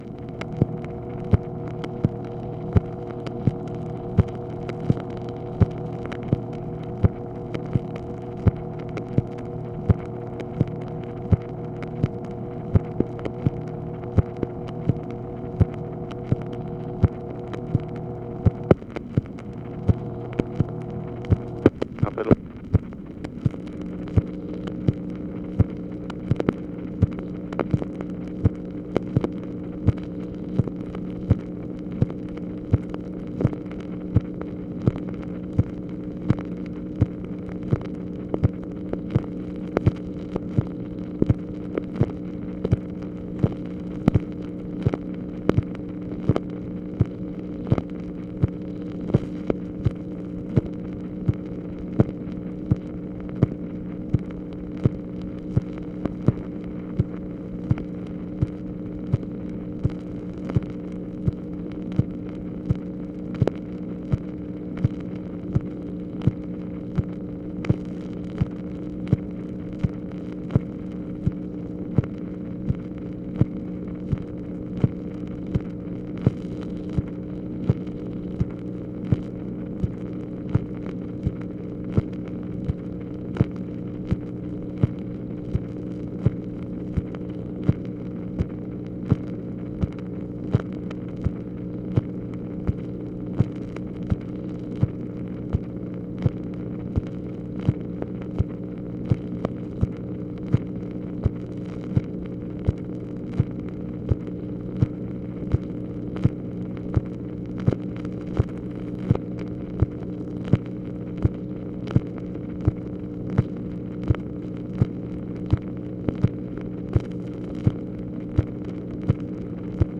MACHINE NOISE, March 25, 1965
Secret White House Tapes | Lyndon B. Johnson Presidency